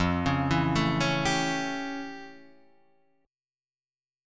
F+M9 chord